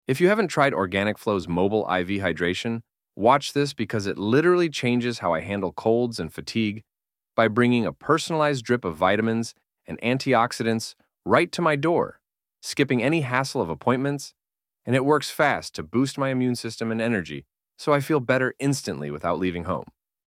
96451-voiceover.mp3